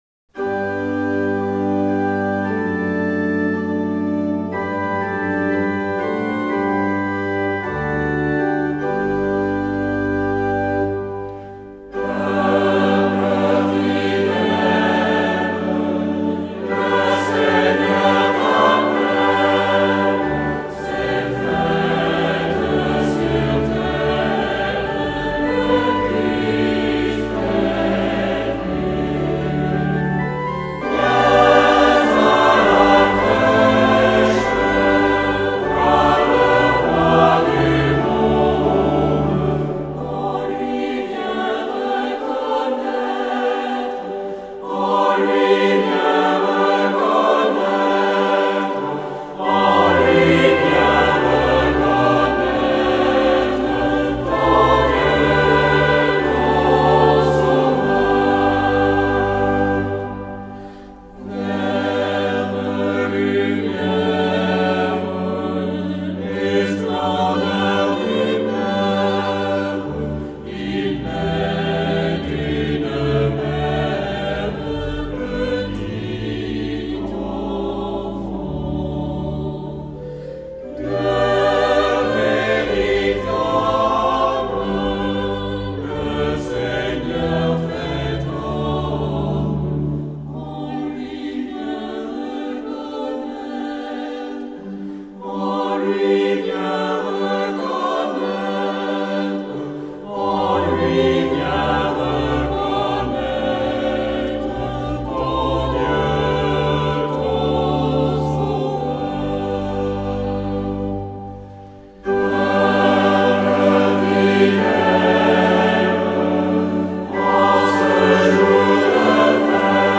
Joyeux Noël
Ensemble-Vocal-lAlliance-Peuple-fidèle.mp3